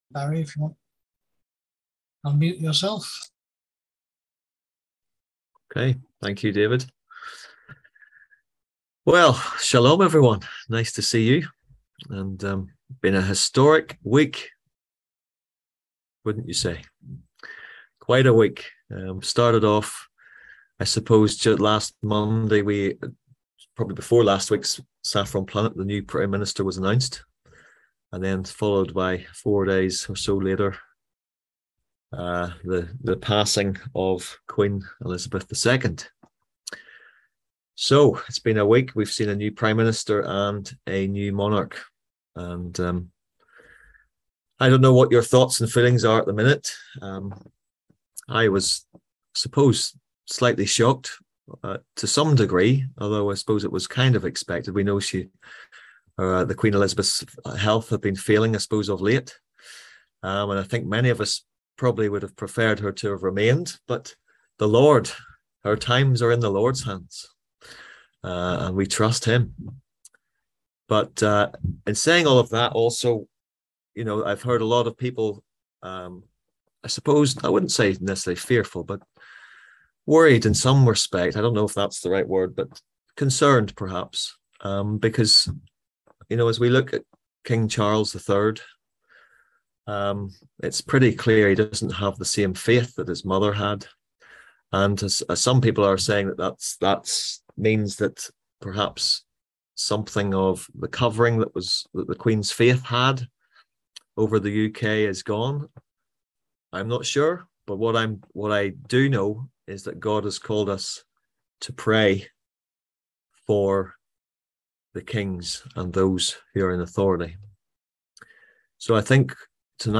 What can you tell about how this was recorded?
On September 12th at 7pm – 8:30pm on ZOOM